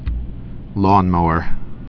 (lônmōər)